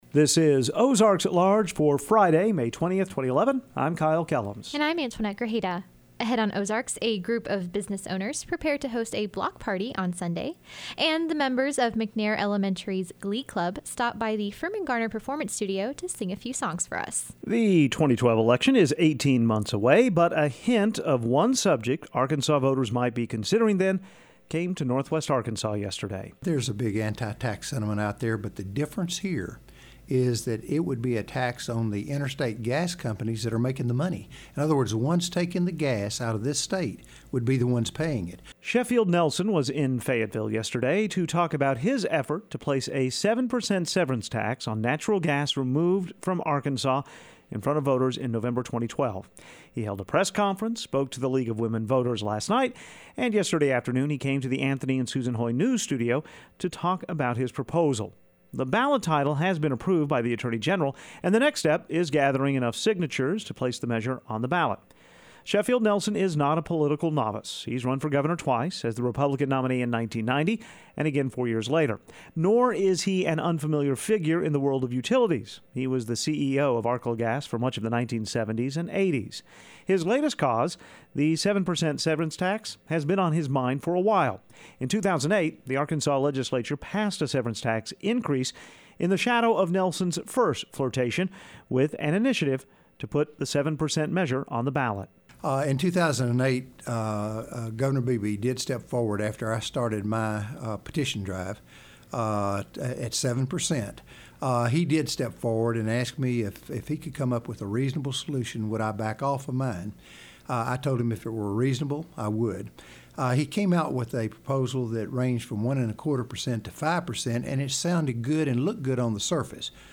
On today's edition of Ozarks, Block Street in Fayetteville prepares for a Block Party, and the McNair Elementary Glee Club stops by the studio for a performance.